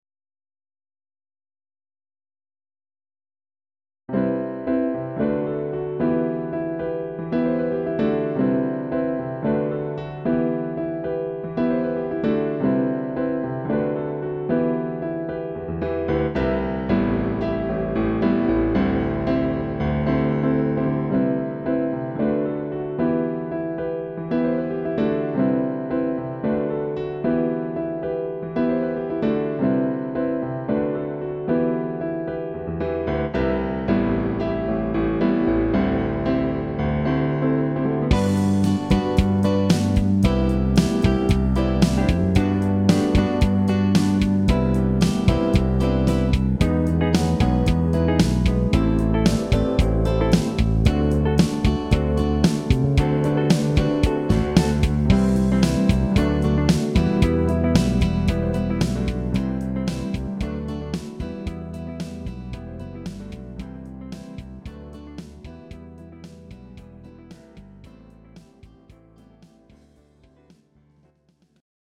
Rock Ballads